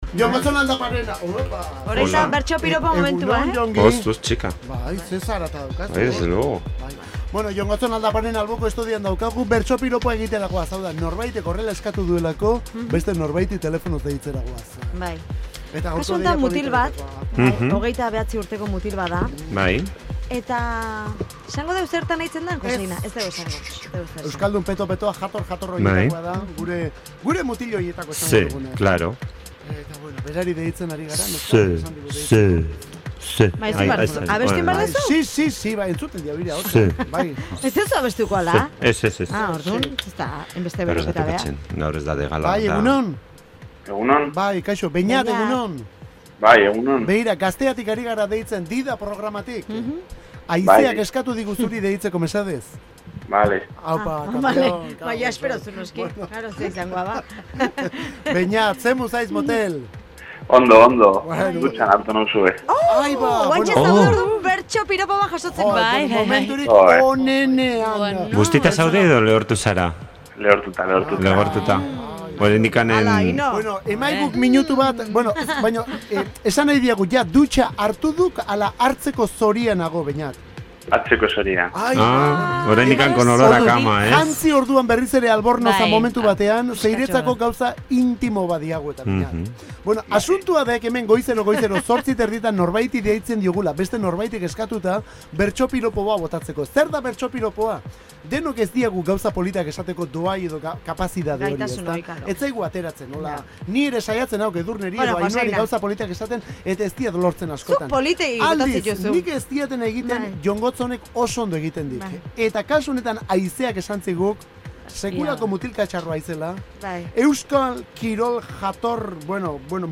'Dida': Norentzat izan ote da gaur bertso-piropoa?
DIDA19_BERTSOPIROPOA.mp3